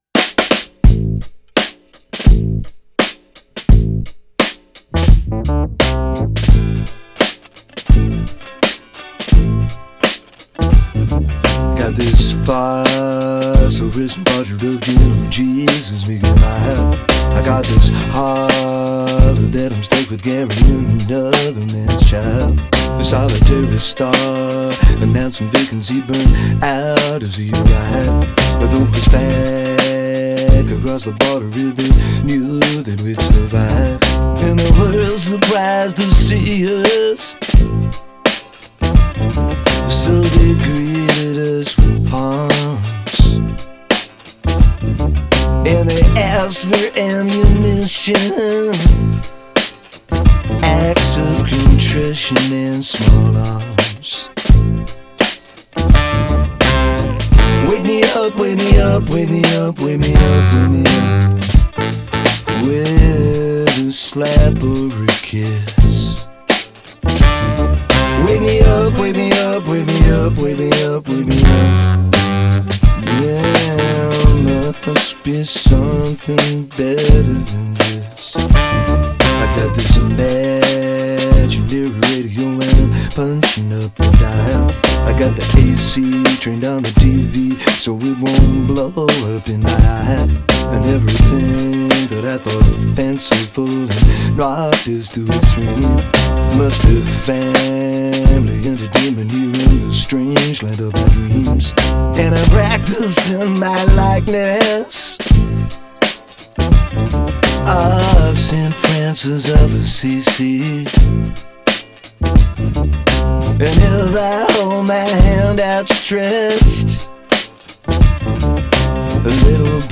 The spare funk groove is bruised and brooding